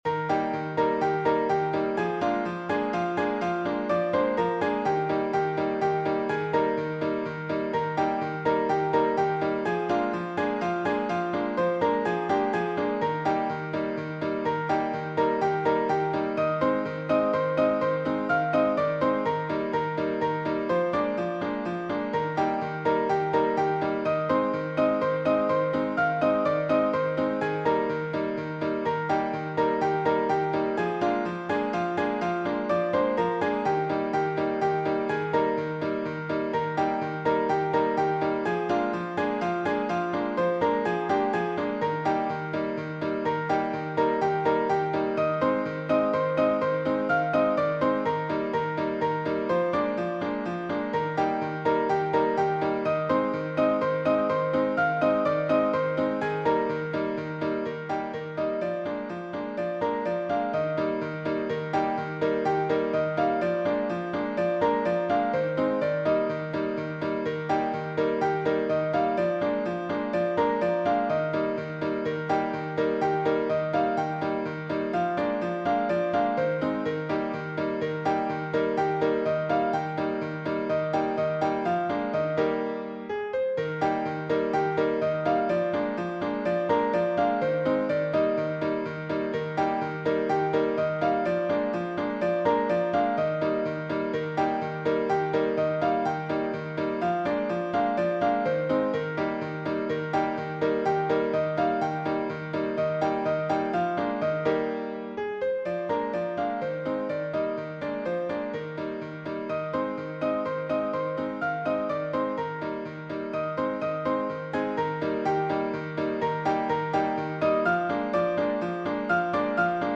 Cheerful
Classic ragtime
Piano and voice